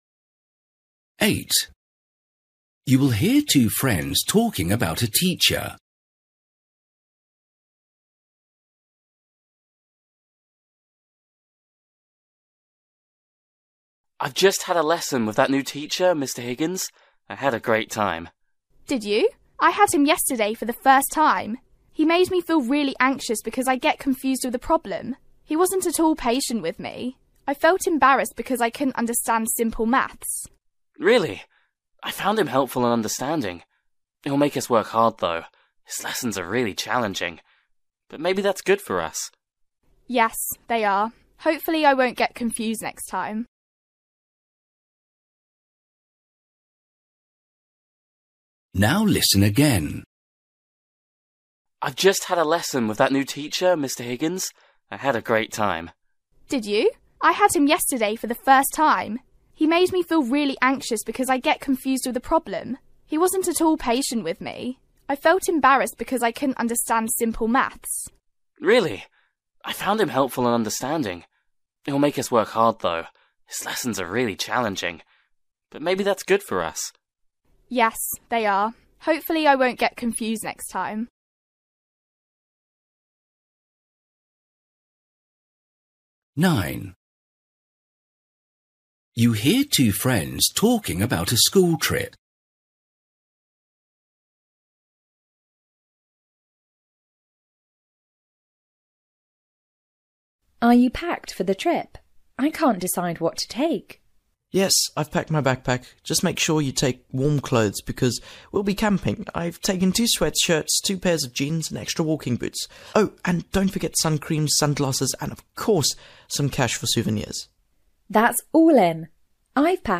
Listening: everyday short conversations
8   You will hear two friends talking about a teacher. They agree that
10   You will hear two friends talking about learning to play tennis. The girl advises the boy to